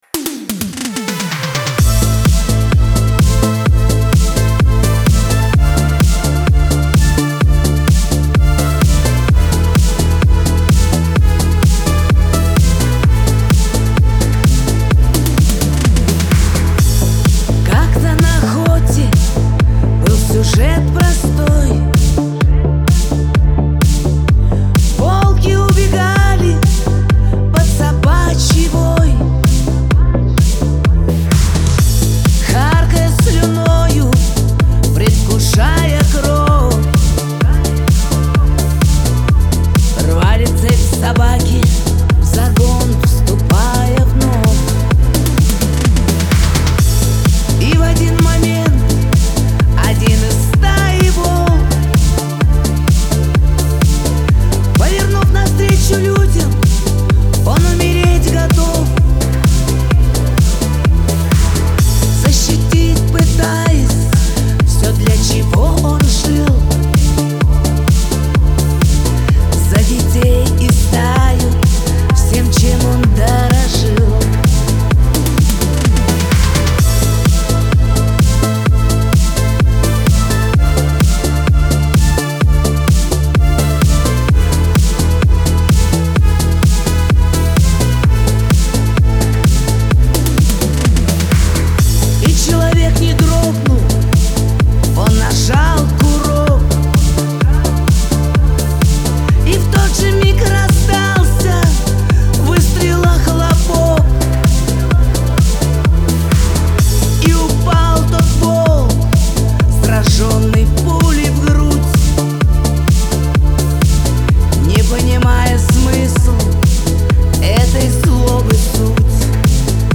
Альбом: шансон